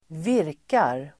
Ladda ner uttalet
virka verb, crochet Grammatikkommentar: A & (x) Uttal: [²v'ir:kar] Böjningar: virkade, virkat, virka, virkar Definition: tillverka av garn med hjälp av virknål Exempel: virka grytlappar (crochet pot holders)
virkar.mp3